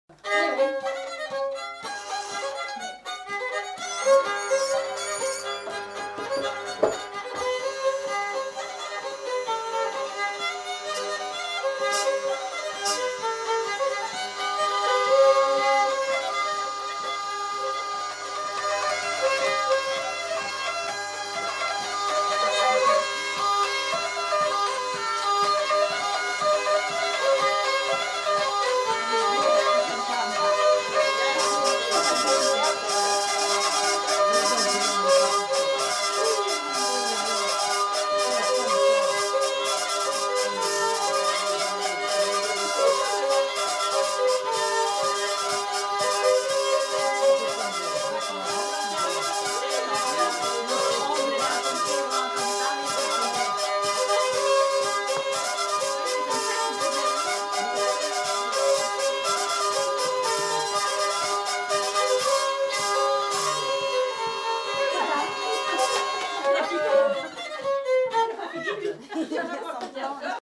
Aire culturelle : Limousin
Genre : morceau instrumental
Instrument de musique : violon ; vielle à roue
Danse : polka
Notes consultables : La vielle à roue est jouée par un des enquêteurs.